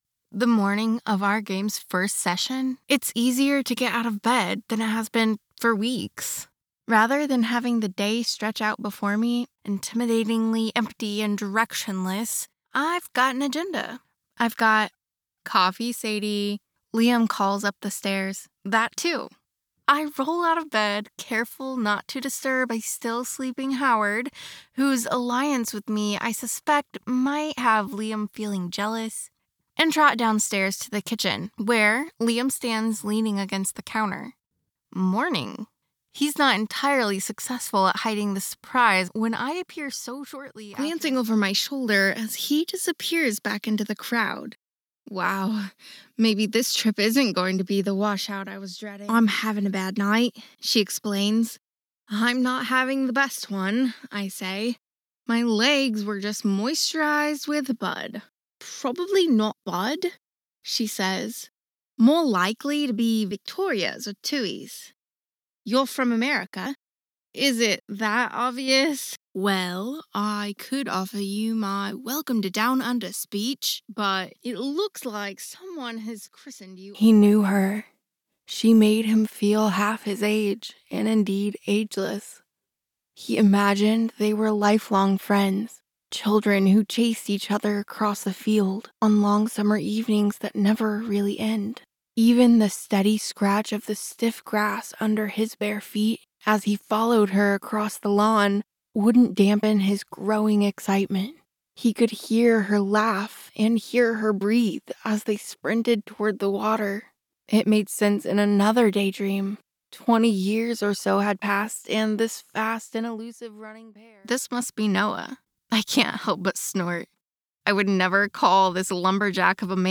Relatable, conversational, real person, believable, soft, warm, sincere, sensual, Persuasive, Friendly, relatable, smooth, funny
Full-time female American voice actor with soft